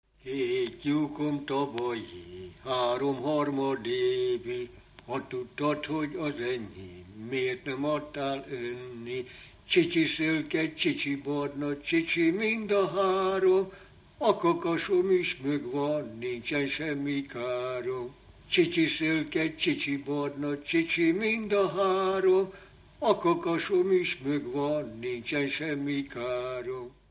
Alföld - Pest-Pilis-Solt-Kiskun vm. - Kunszentmiklós
ének
Stílus: 6. Duda-kanász mulattató stílus